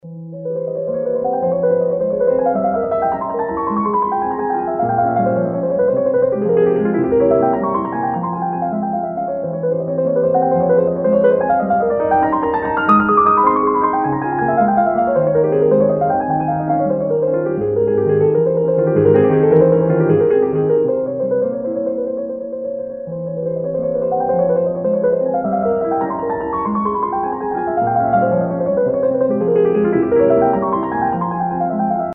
Рингтоны » Классические